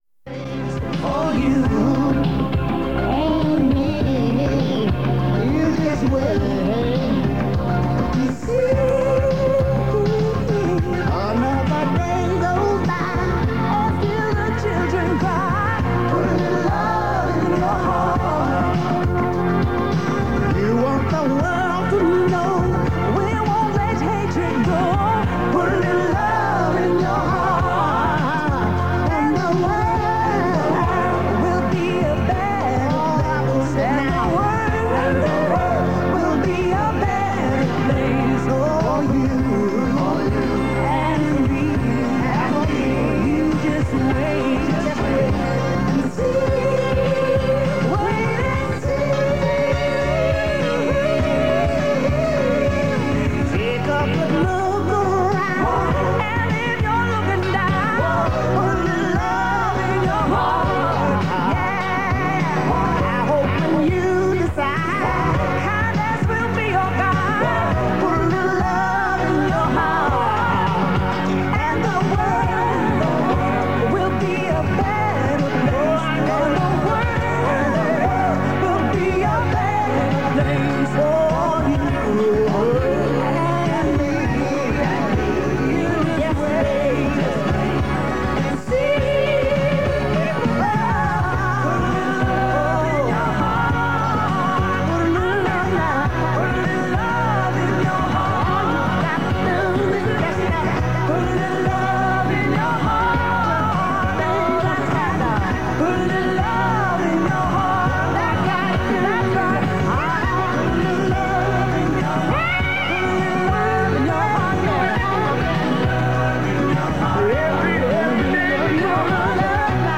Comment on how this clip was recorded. Radio Nova International was a licensed satellite station on Intelsat VA F11 but remained connected to the Irish pirate radio scene through AM/FM relays on the other side of the Irish Sea.